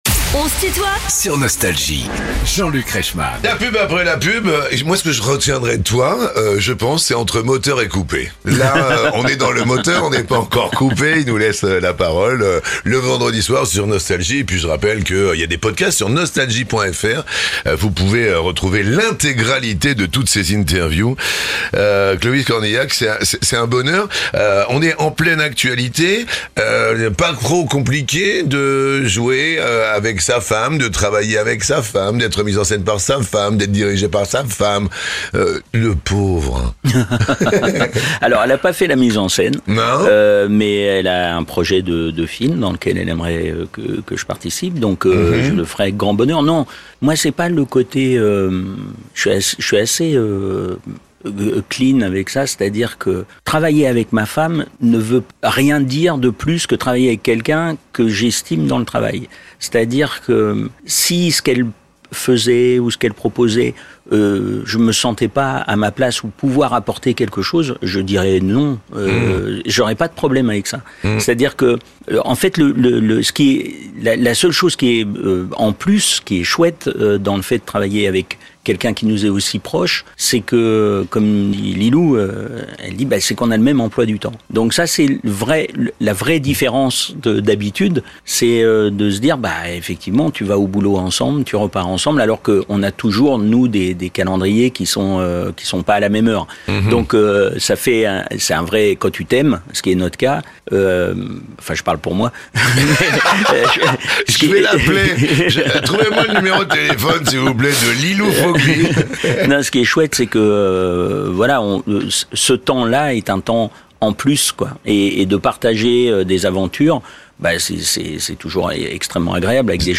Clovis Cornillac est l'invité de "On se tutoie ?..." avec Jean-Luc Reichmann (Partie 2) ~ Les interviews Podcast